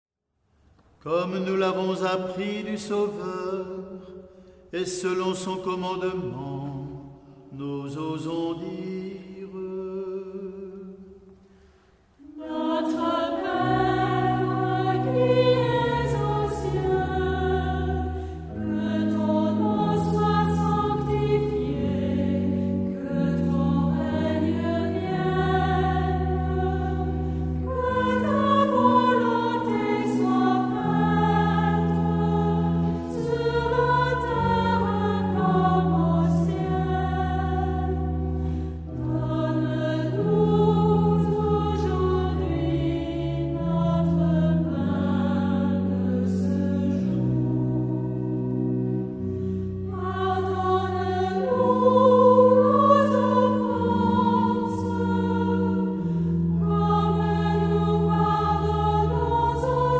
Genre-Style-Forme : Sacré ; Prière
Caractère de la pièce : recueilli
Type de choeur : unisson
Instruments : Orgue (1)
Tonalité : do majeur